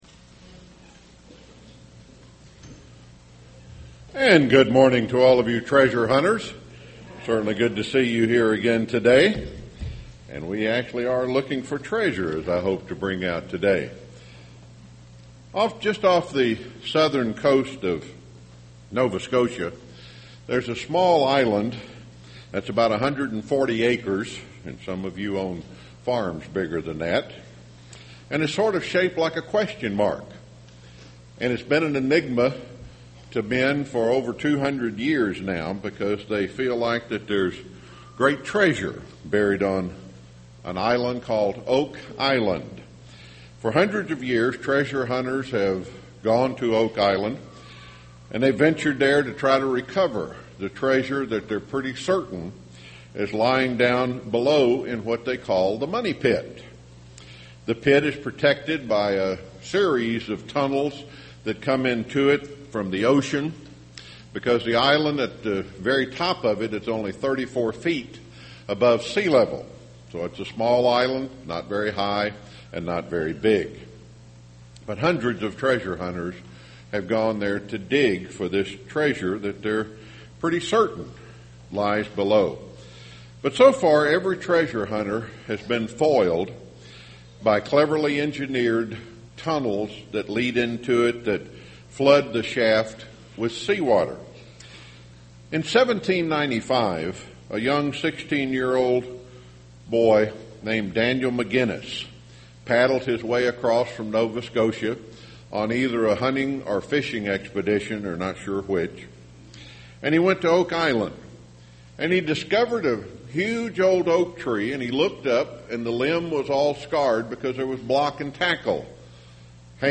This sermon was given at the Gatlinburg, Tennessee 2011 Feast site.